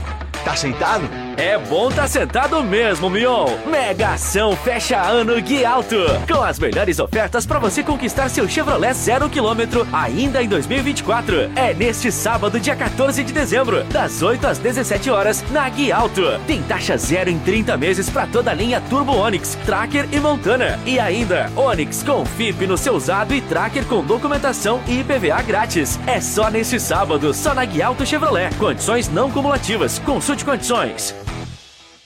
ANIMADO: